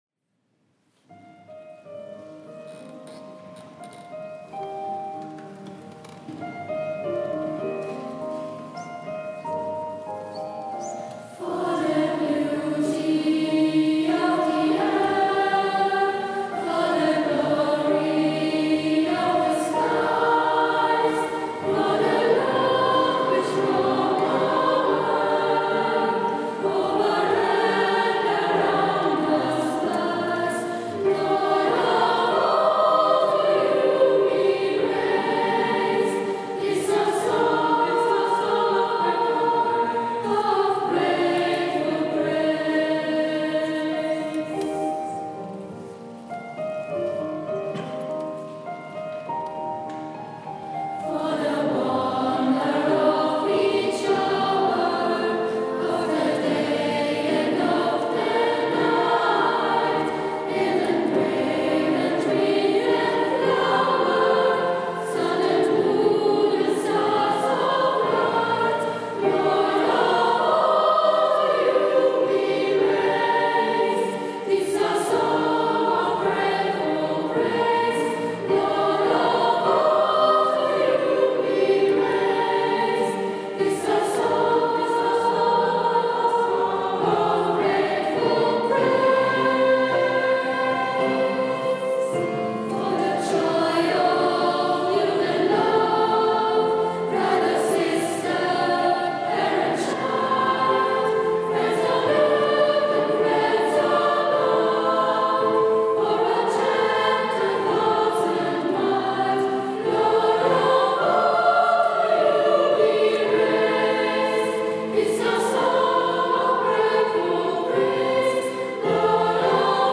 Konzert der Gesangsklasse
in der Franziskanerkirche St.Pölten mit dem Orchester des Musikvereins St.Pölten
Basso Continuo